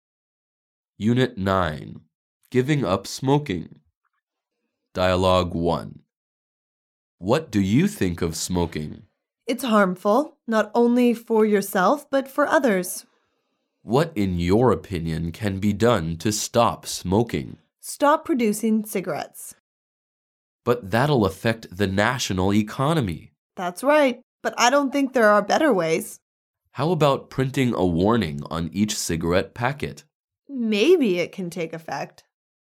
Dialouge 1